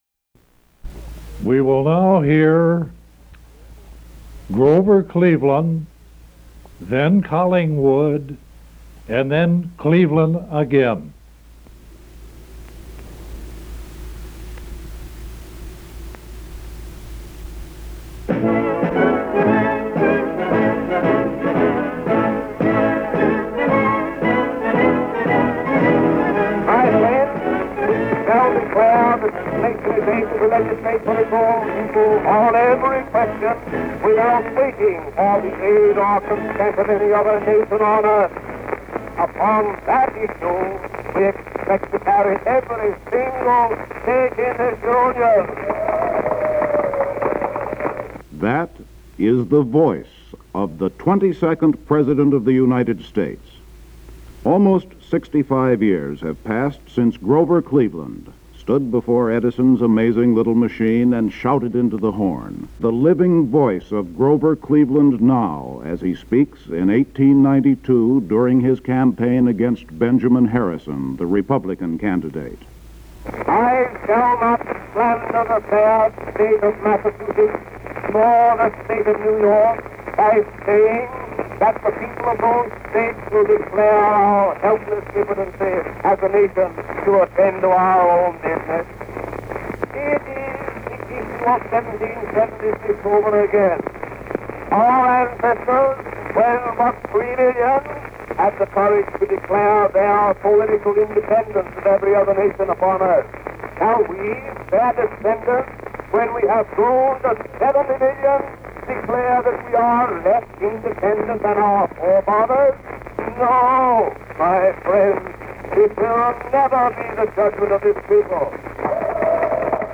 Democrat Grover Cleveland, during his 1892 presidential campaign against Republican Benjamin Harrison, delivers remarks invoking the heroes of 1776 as models of independent thought. Cleveland was originally recorded on an Edison Cylinder.